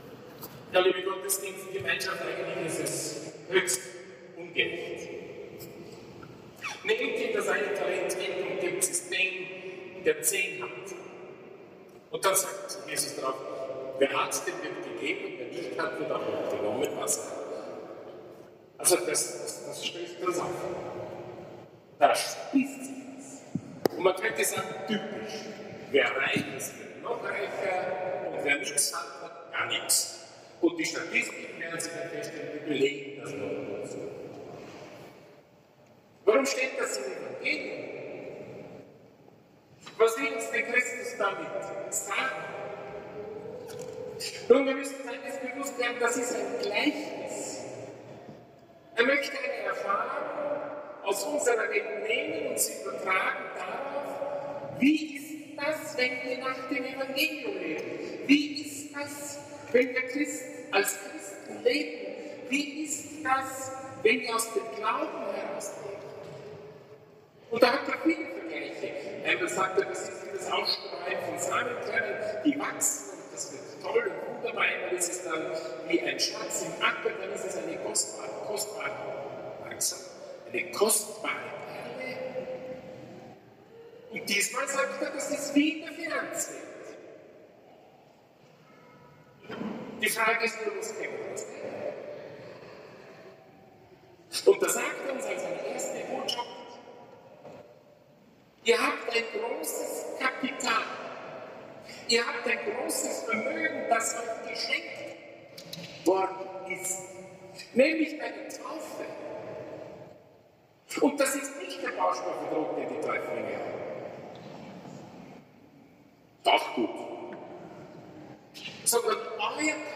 Überdioezesane Firmung Sonntagberg (10h30)
Sonntagberg, 09.06.2025 Überdiözesane Firmung in der Basilika Sonntagberg .
Lesungen und Fürbitten trugen die Firmlinge und Paten selbst vor.